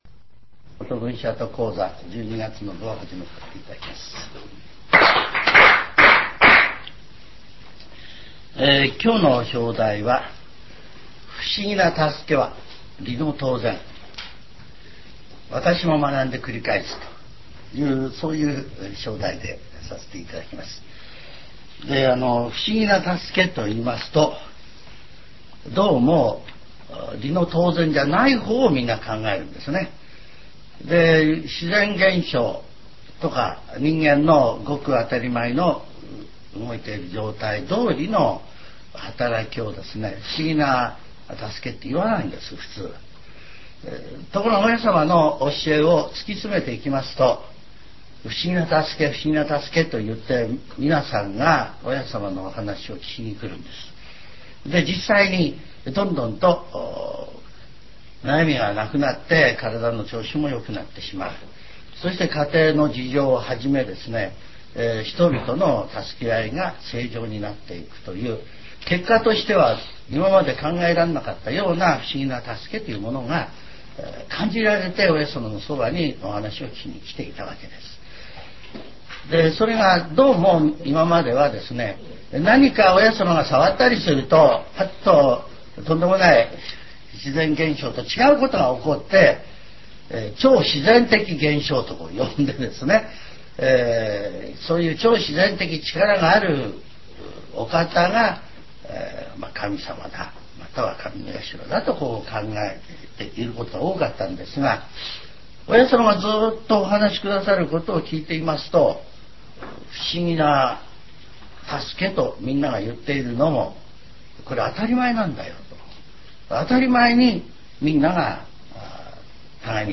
全70曲中4曲目 ジャンル: Speech